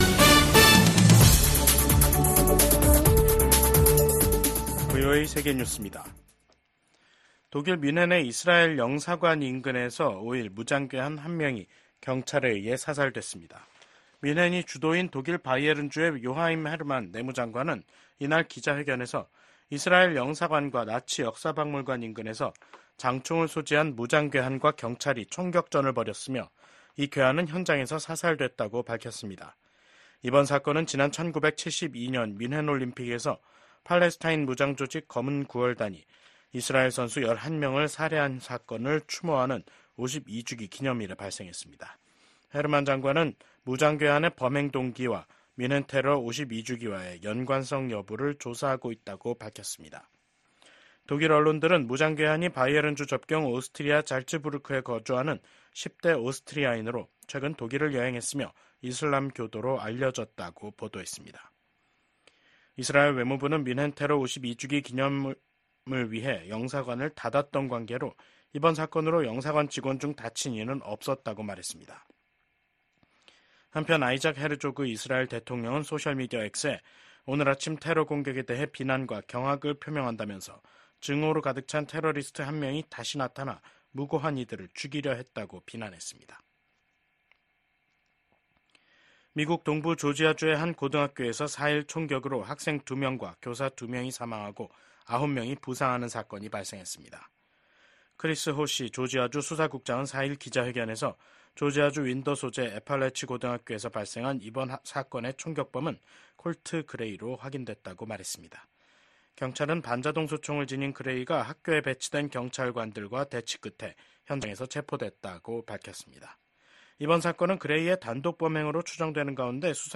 VOA 한국어 간판 뉴스 프로그램 '뉴스 투데이', 2024년 9월 5일 3부 방송입니다. 미국과 한국이 북한의 도발에 대한 강력한 대응 의지를 재확인했습니다. 북한이 25일만에 또 다시 한국을 향해 쓰레기 풍선을 날려 보냈습니다. 국제 핵실험 반대의 날을 맞아 북한의 핵과 미사일 개발을 규탄하는 목소리가 이어졌습니다.